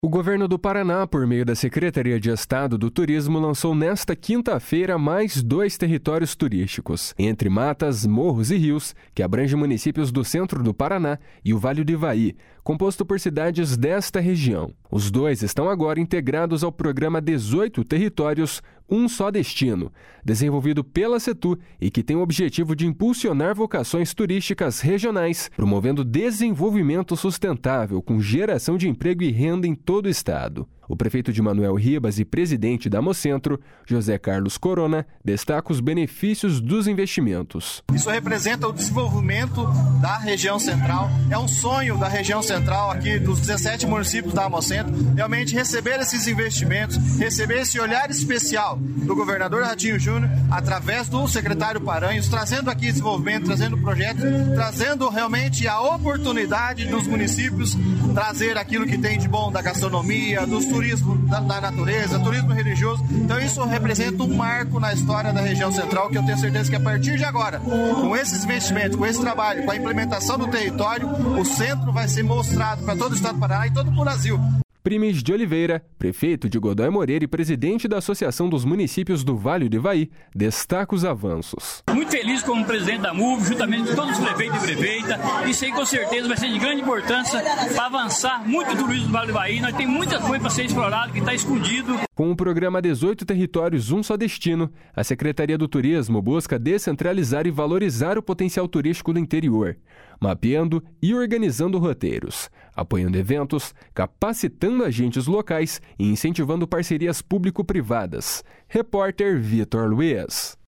O prefeito de Manoel Ribas e presidente da Amocentro, José Carlos Corona, destaca os benefícios dos investimentos.
Primis de Oliveira, prefeito de Godoy Moreira e presidente da Associação dos Municípios do Vale do Ivaí, destaca os avanços.